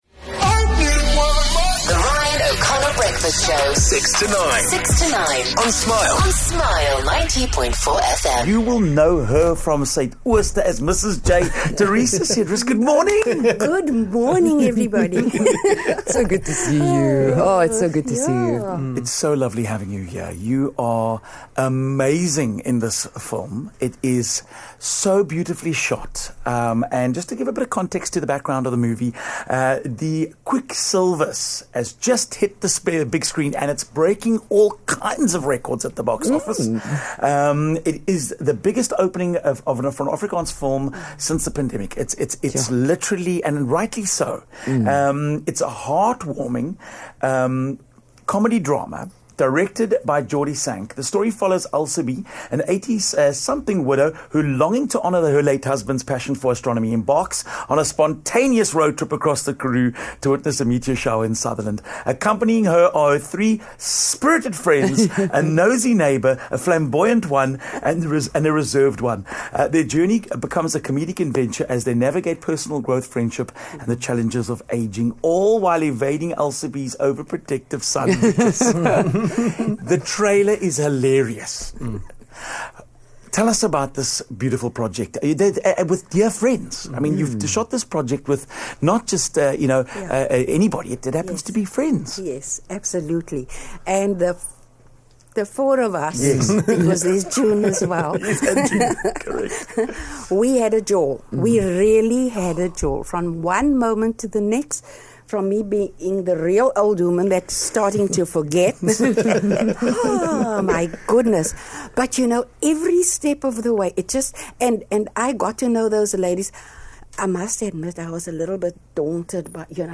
joined us in studio.